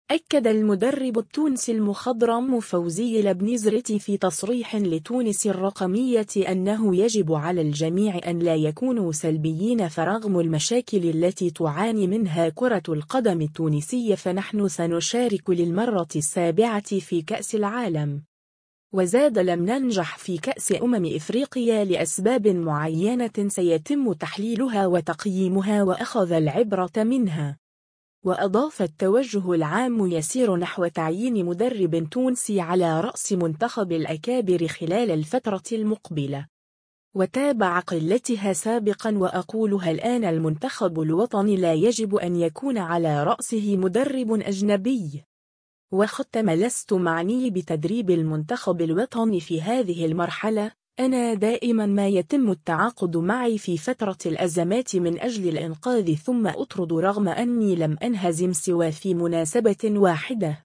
أكّد المدرّب التونسي المخضرم فوزي البنزرتي في تصريح لتونس الرقمية أنّه يجب على الجميع أن لا يكونوا سلبيين فرغم المشاكل التي تعاني منها كرة القدم التونسية فنحن سنشارك للمرّة السابعة في كأس العالم.